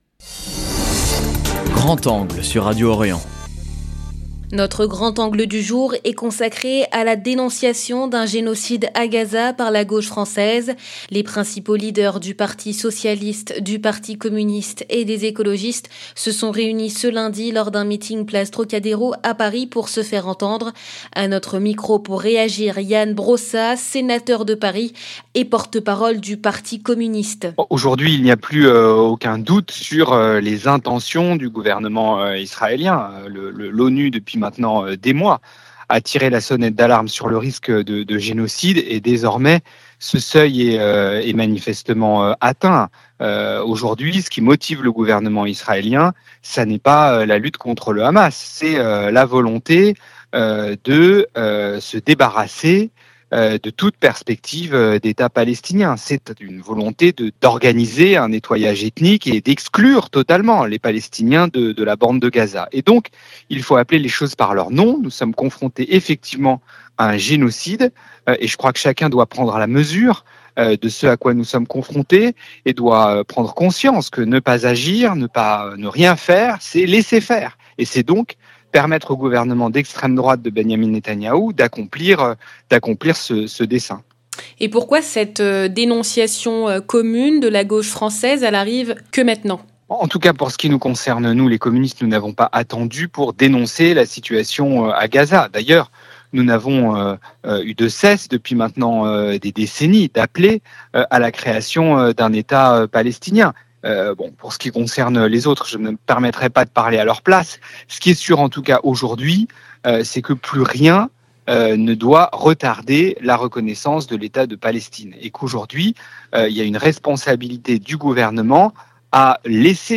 À notre micro, Ian Brossat, sénateur de Paris et porte-parole du Parti communiste, a réagi. 0:00 6 min 4 sec